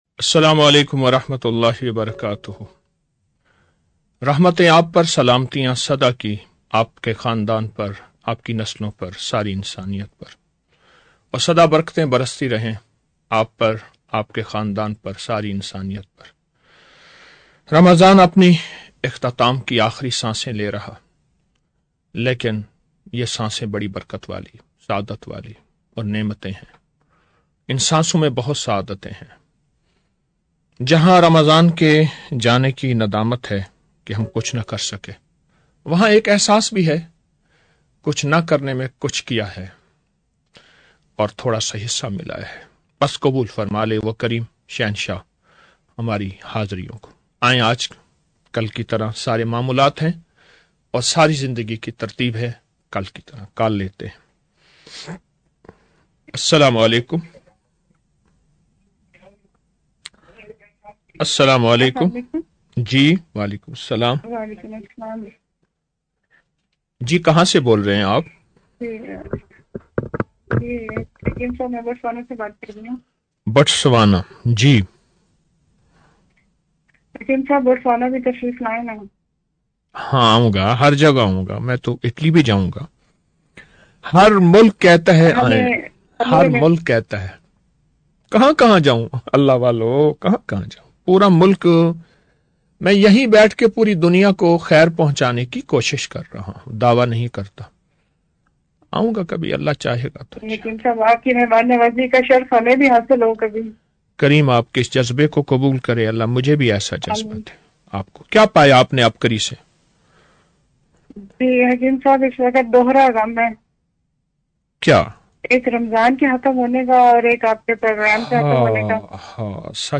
Speeches about Ramadan ul Mubarak. The talk is aimed to draw the Ummah closer to Allah Subhan O Wa Tallah and to provide the Ummah with a solution to their world and hereafter through Quran, Sunnah and practices of the faithful.